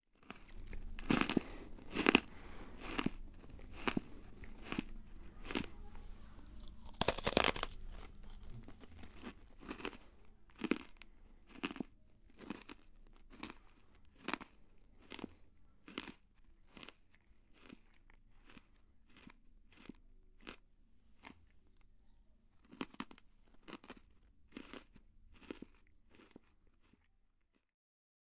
用力咀嚼
描述：听起来非常美味
Tag: 用力咀嚼 嘎吱